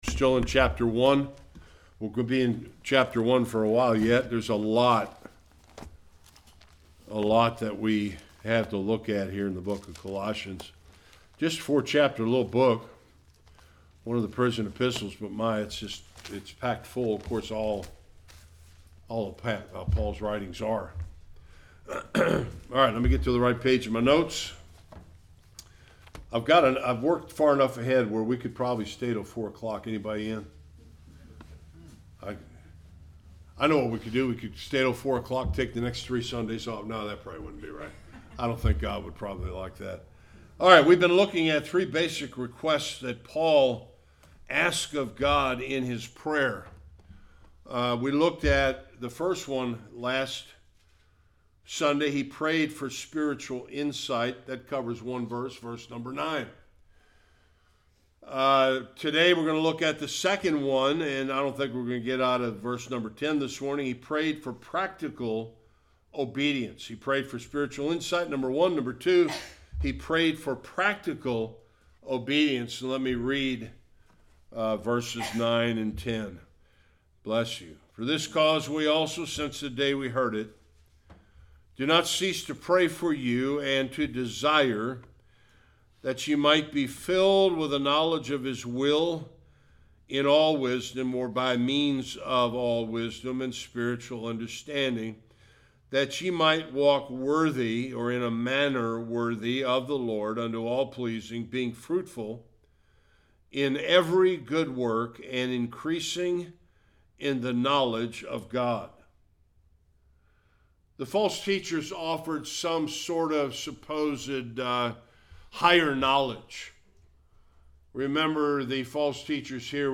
10-12 Service Type: Sunday Worship Paul made three basic requests of the LORD in his prayer for the believers at Colossae.